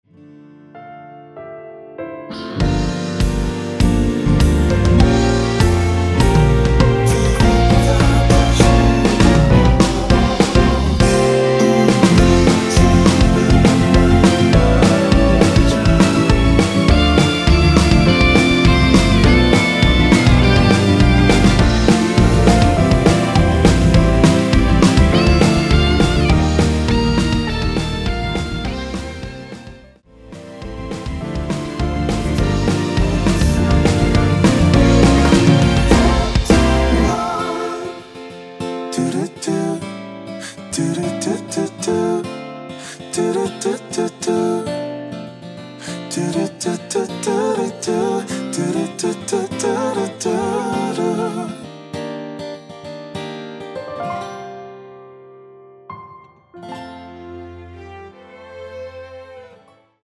원키에서(-1)내린 코러스 포함된 MR입니다.
앞부분30초, 뒷부분30초씩 편집해서 올려 드리고 있습니다.